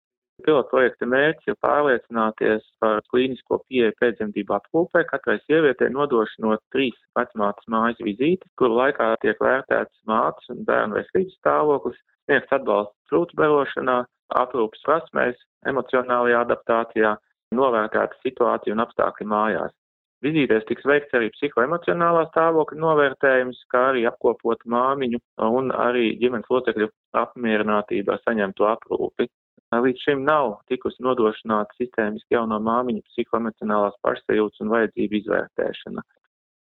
Saruna